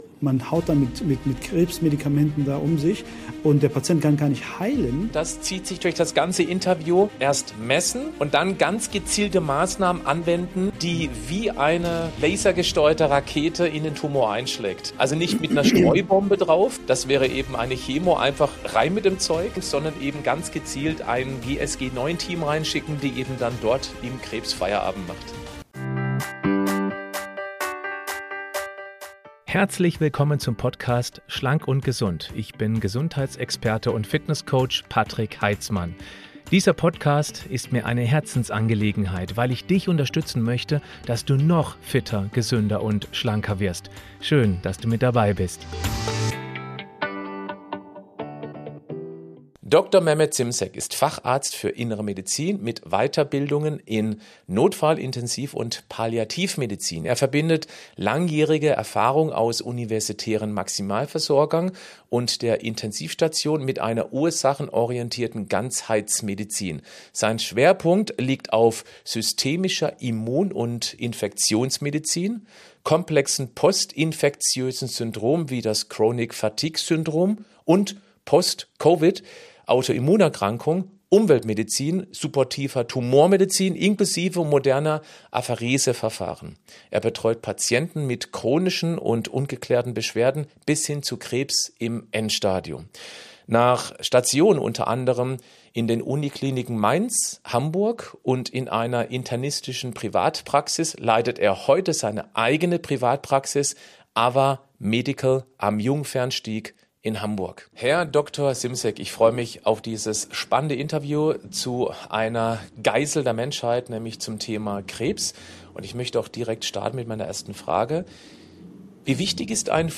520 - Chemotherapie VERALTET? Das solltest du beachten! Interview